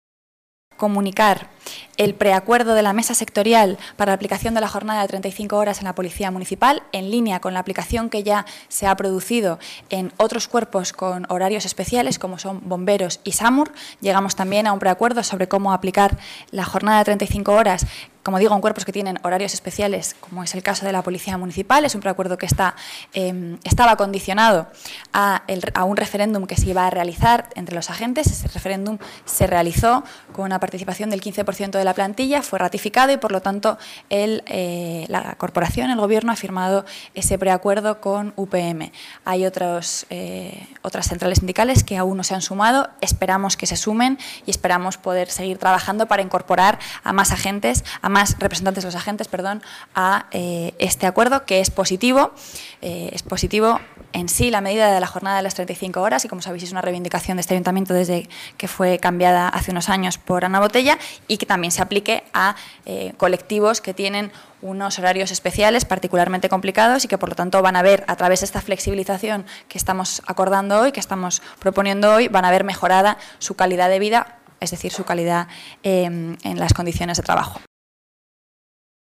Nueva ventana:Rita Maestre habla de la jornada de 35 horas de los servicios de emergencias municipales